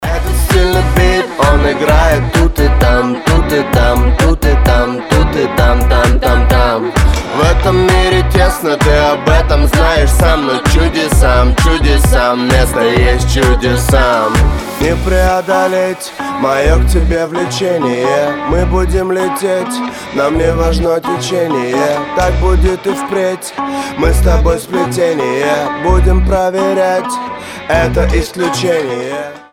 • Качество: 320, Stereo
ритмичные
стильные
классный бит